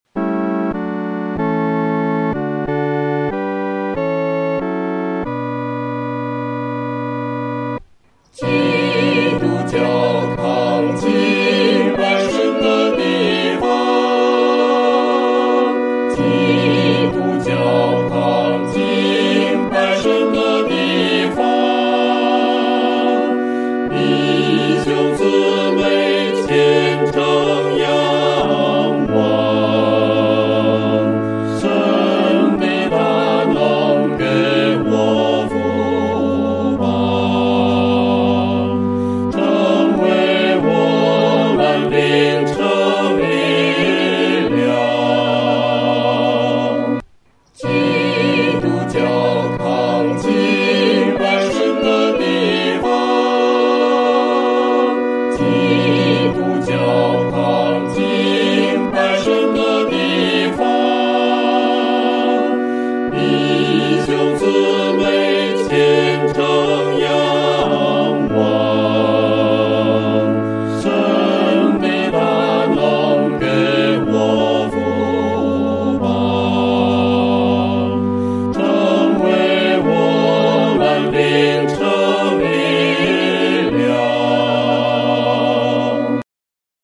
合唱
四声
这首诗歌宜用不快的中速来弹唱，声音要饱满。